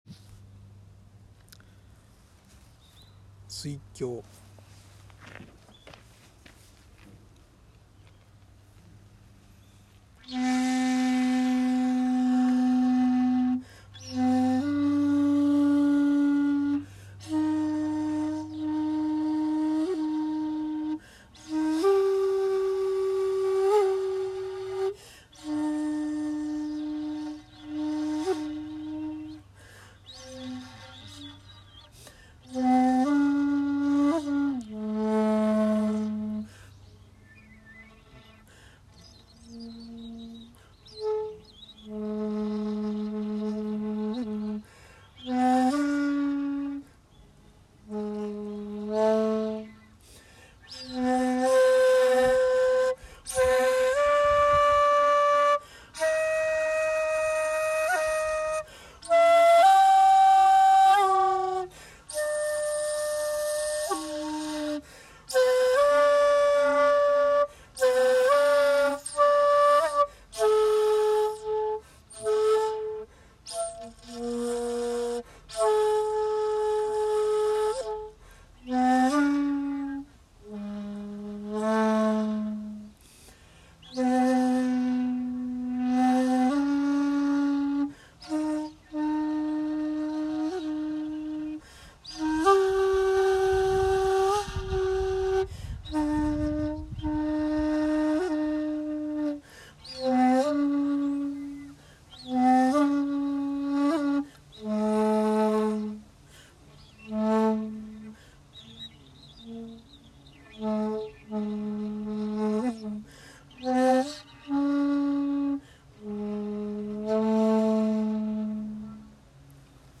参拝し、納経、尺八を吹奏しました。
（尺八音源：志度寺本堂にて「水鏡」）